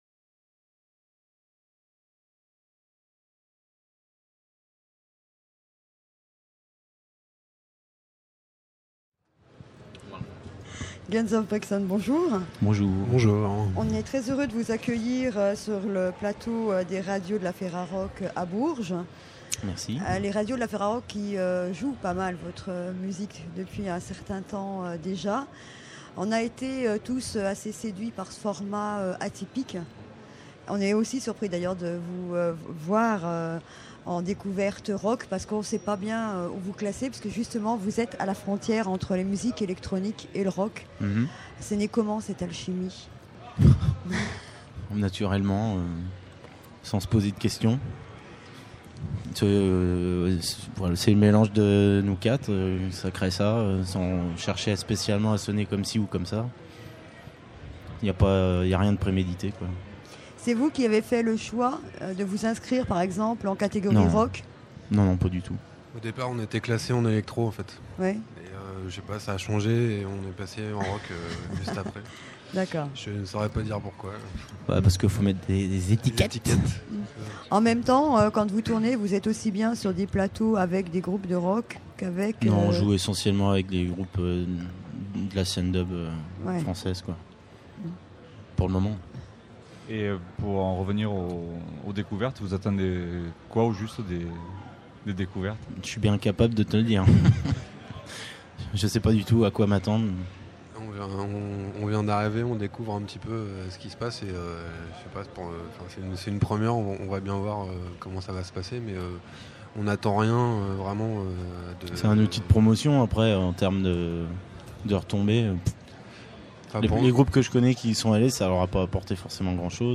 Guns Of Brixton Festival du Printemps de Bourges 2006 : 40 Interviews à écouter !